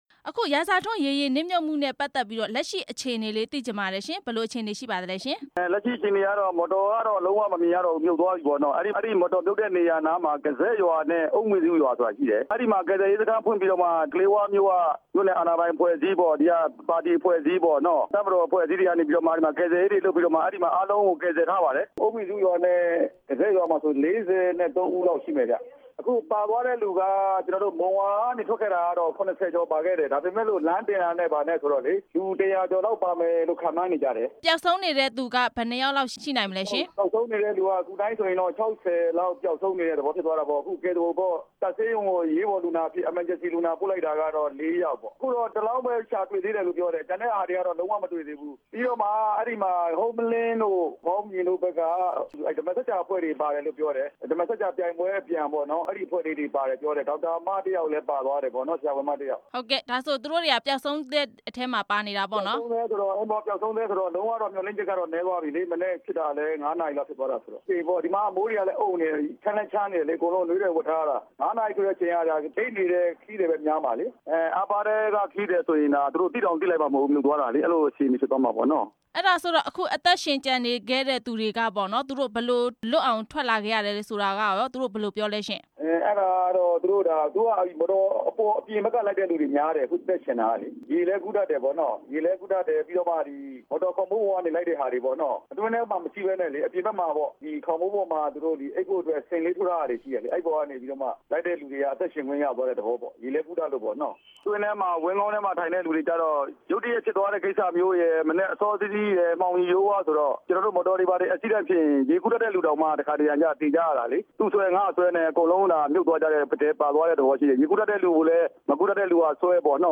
အမြန်ရေယာဉ် နစ်မြုပ်ရာတွင် ကယ်ဆယ်ရေးလုပ်ပေးနေတဲ့ အကြောင်းမေးမြန်းချက်